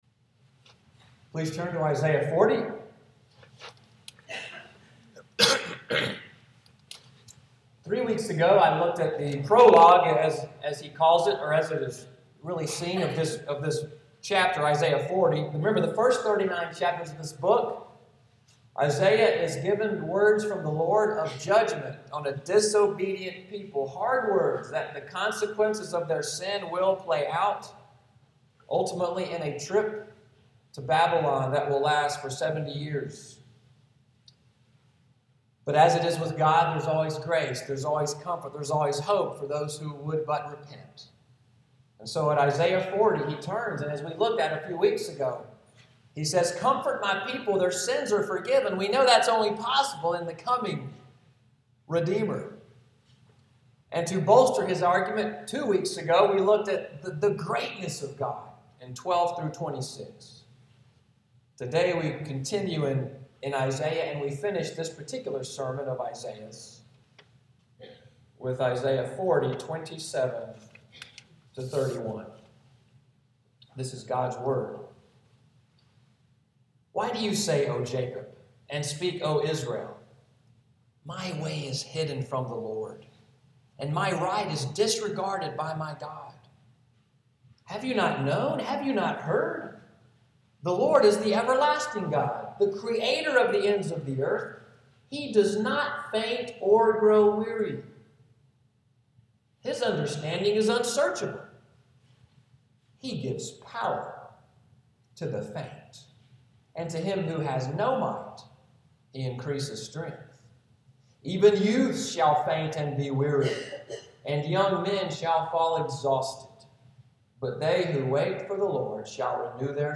Order of worship & sermon outline